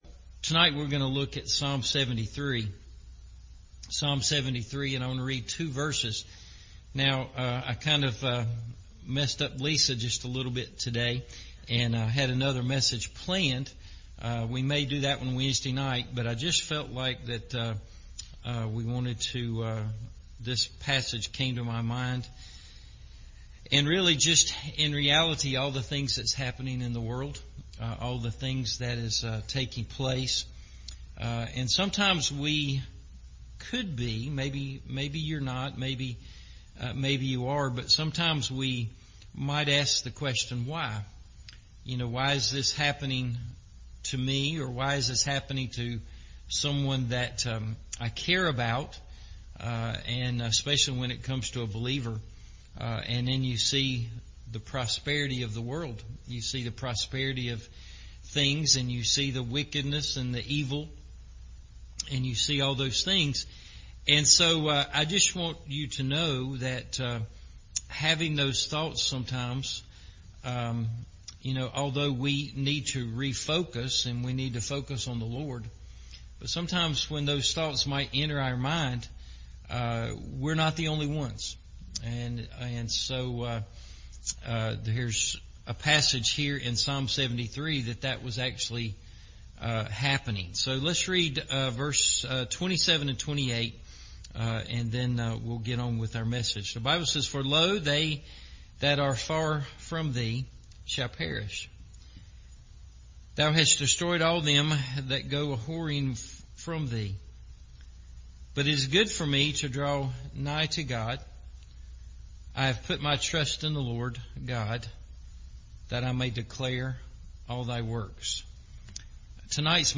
A New Perspective – Evening Service